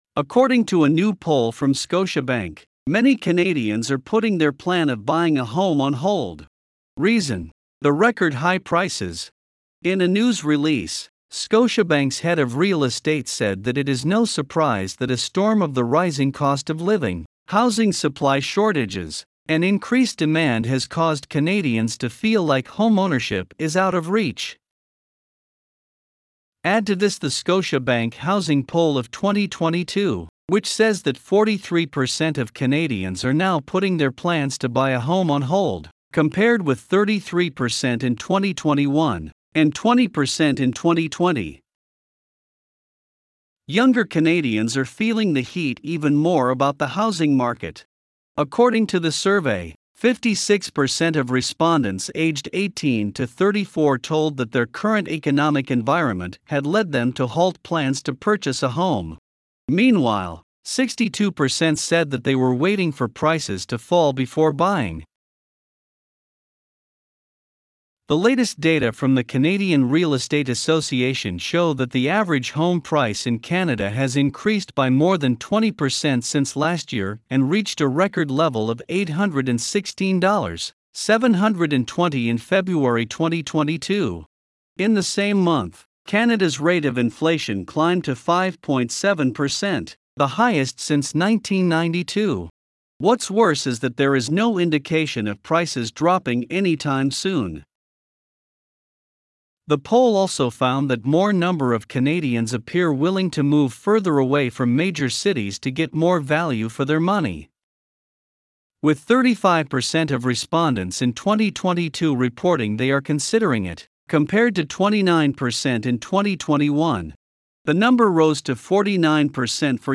Voiceovers-Voices-by-Listnr_24.mp3